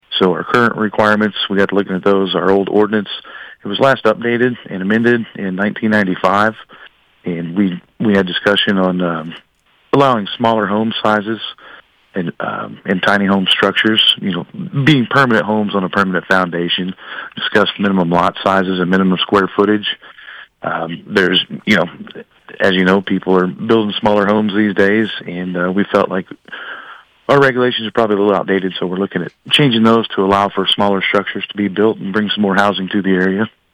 Mayor Ronnie Stowers said the board discussed the city’s current regulations and came to the conclusion they were outdated.